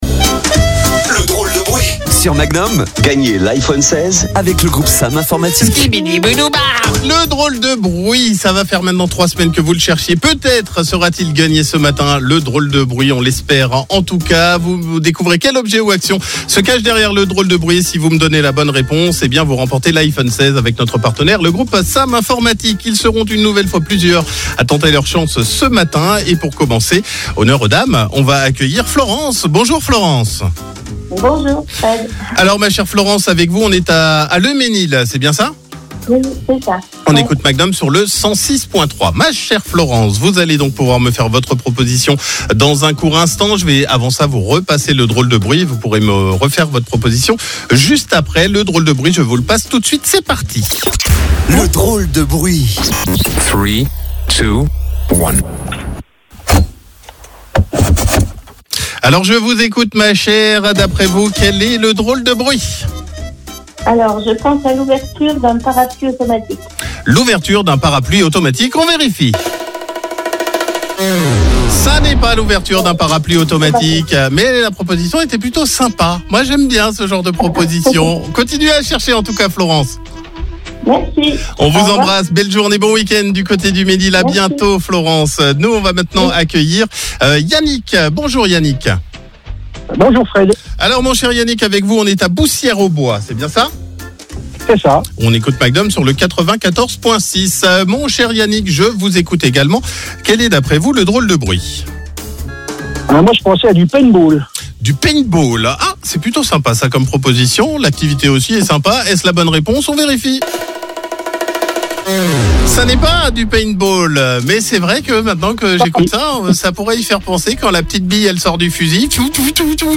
Tentez de gagner un iPhone 16 en jouant au "Drôle de Bruit" sur Magnum La Radio ! Découvrez quel objet ou quelle action se cache derrière le DROLE DE BRUIT en écoutant CLUB MAGNUM entre 9h et 13h.